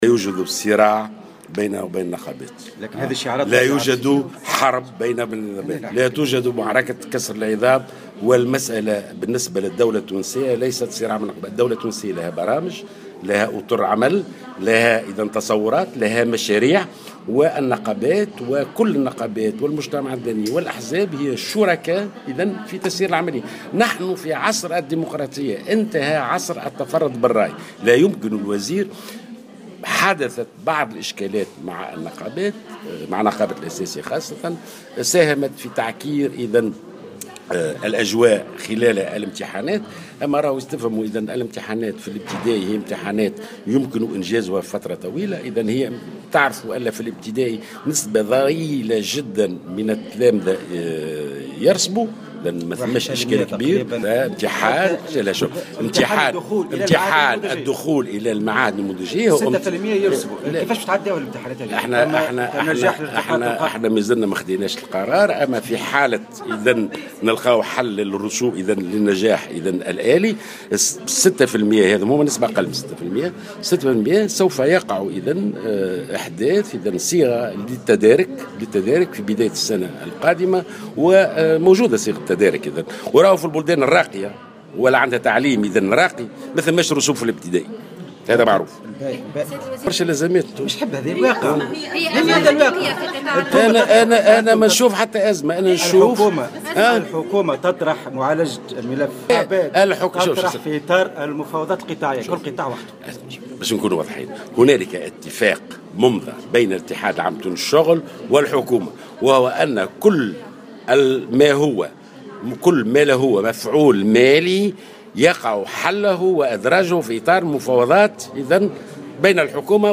بين وزير التربية ناجي حلول في تصريح إعلامي صباح اليوم الاربعاء أن الوزارة ليست في معركة مع نقابات التعليم وخاصة نقابة التعليم الأساسي، مضيفا أن جميع هذه الأطراف شريكة في المنظومة التربوية في بلد ديمقراطي.